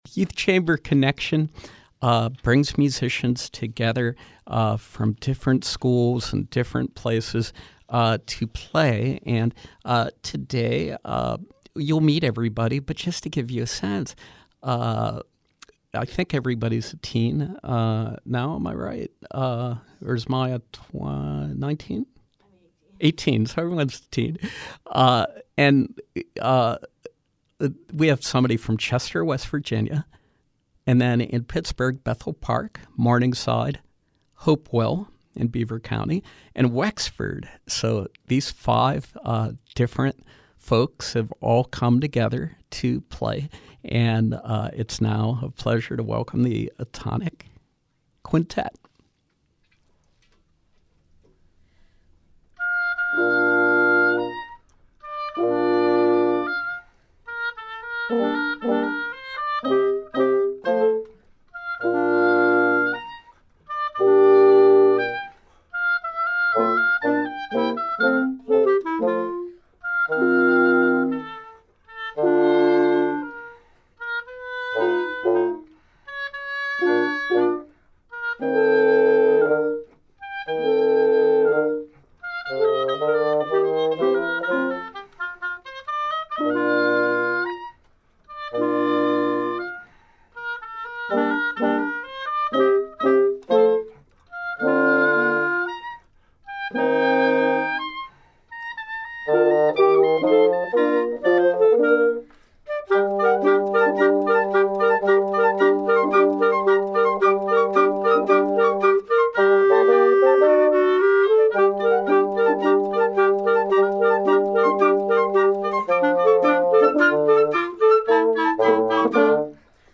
flute
oboe
clarinet
bassoon
French horn